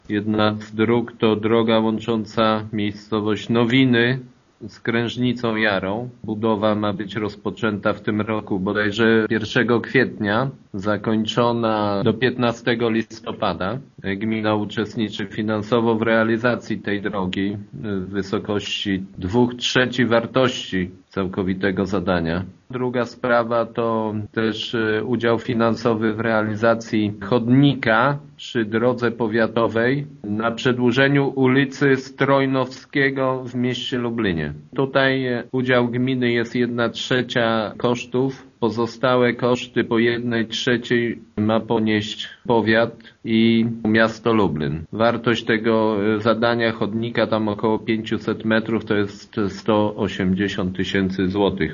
„Jedna dotyczy budowy drogi Niedrzwica Duża – Prawiedniki – Lublin, natomiast druga budowy chodnika przy trasie Wólka Abramowicka – Dominów” – mówi wójt Gminy Głusk Jacek Anasiewicz: